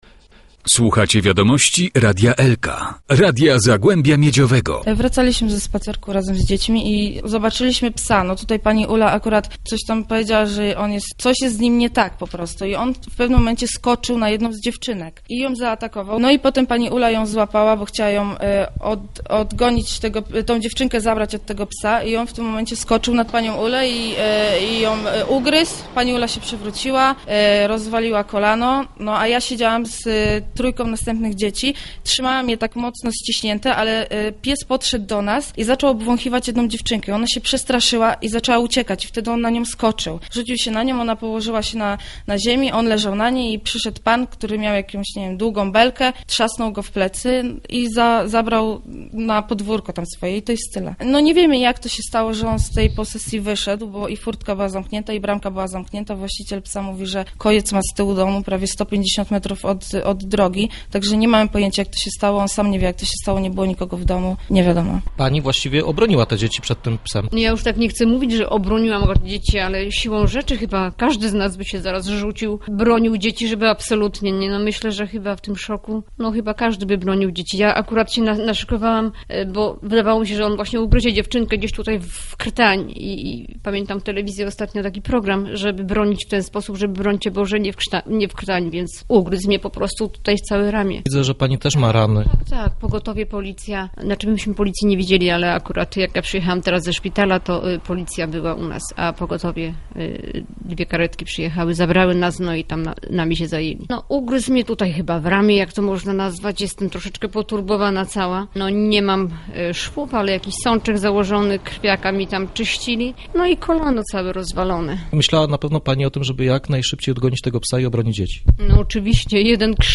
Oto relacja nauczycielek.